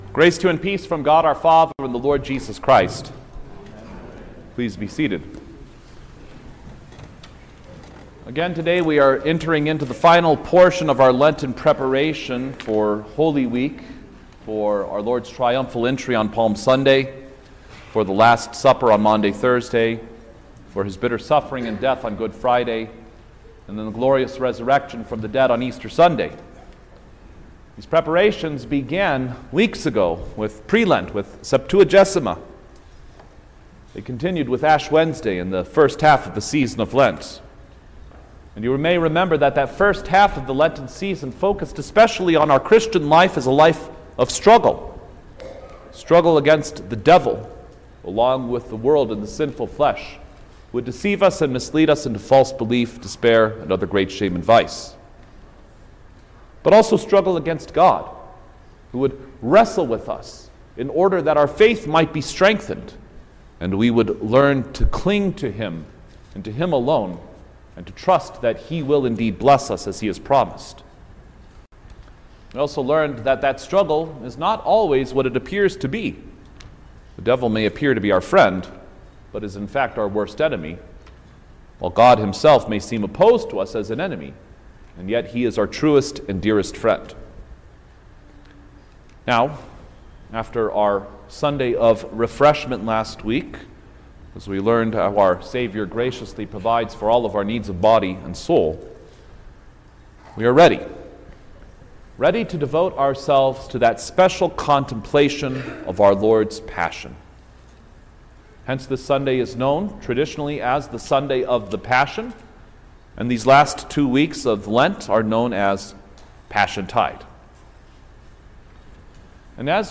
April-6_2025_Fifth-Sunday-in-Lent_Sermon-Stereo.mp3